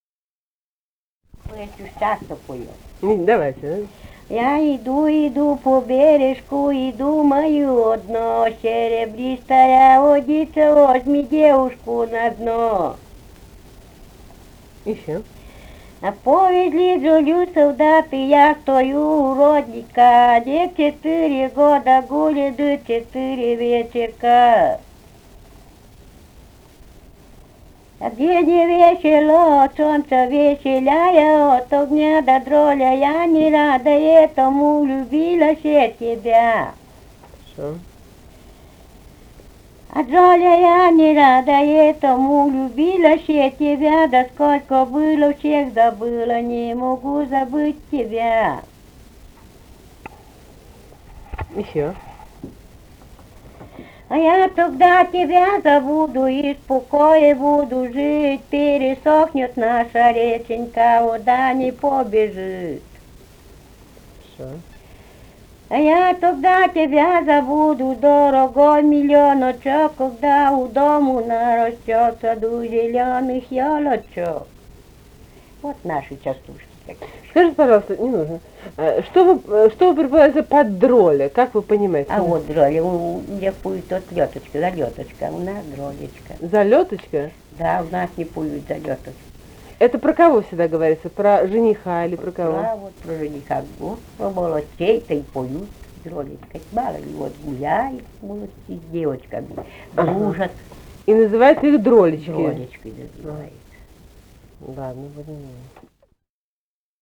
«Я иду, иду по бережку» (частушки).